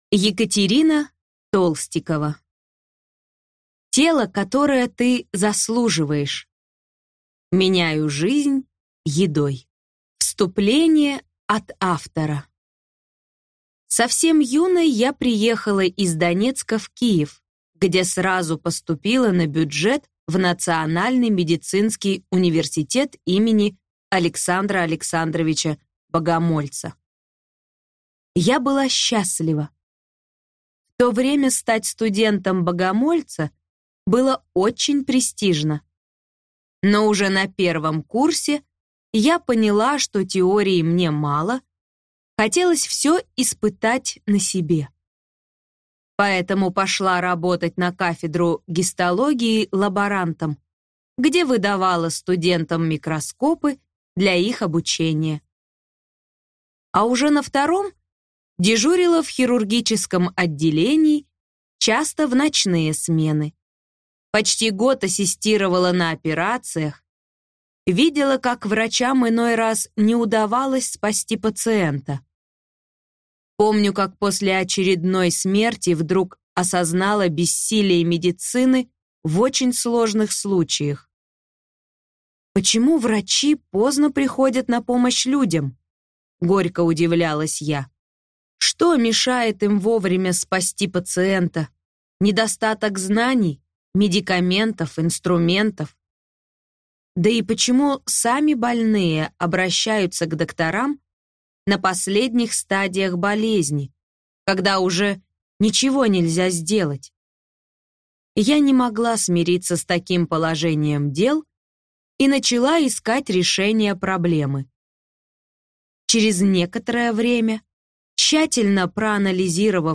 Аудиокнига Тело, которое ты заслуживаешь. Меняю жизнь едой | Библиотека аудиокниг